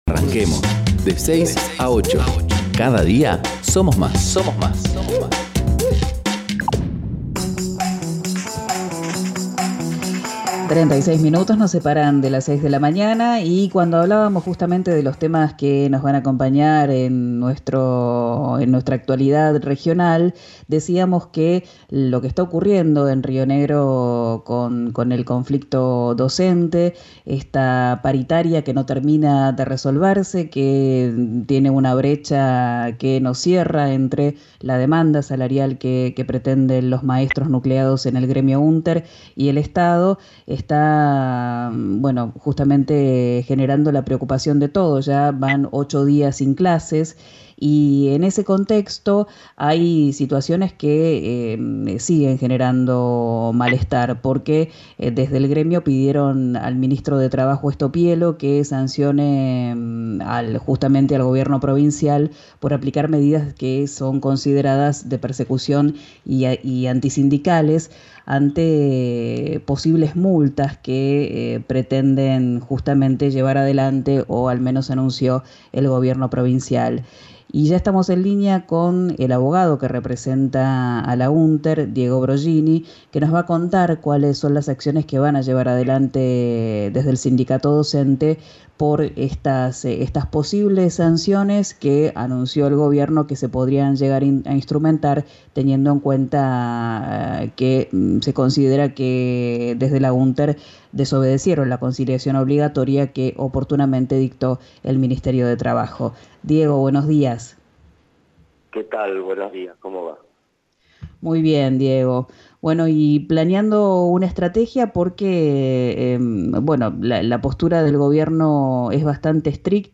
dialogó con RÍO NEGRO RADIO